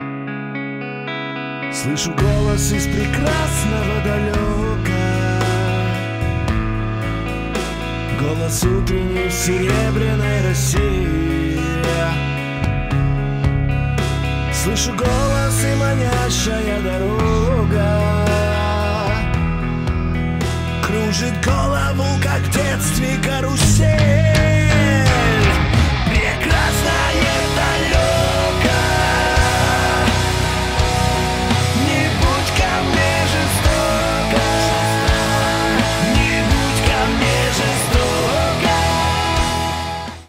• Качество: 320, Stereo
Cover
Alternative Rock
Hard rock
русский рок
Замечательный рок-кавер